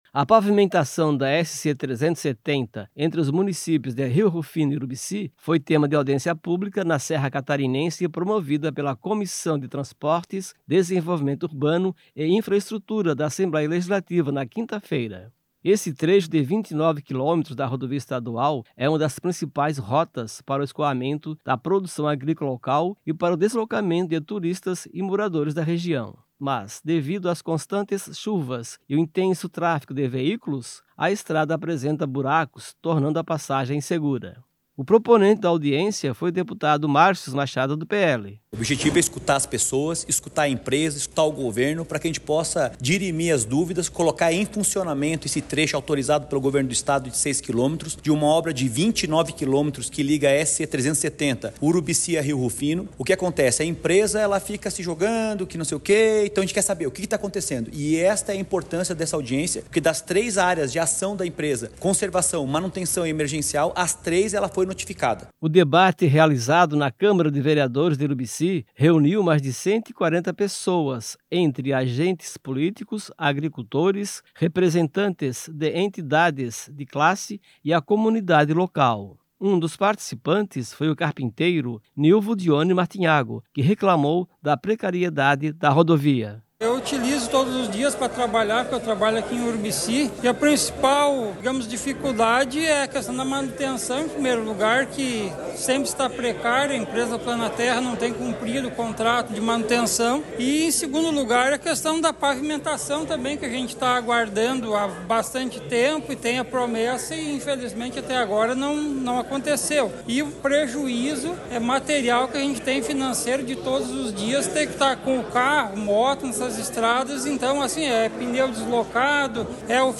Entrevistas com:
- deputado Marcius Machado (PL), proponente da audiência;
- Mariza Costa, prefeita de Urubici;
- Jerry Comper, Secretário de Estado da Infraestrutura e Mobilidade..